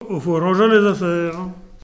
Localisation Nieul-sur-l'Autise
Catégorie Locution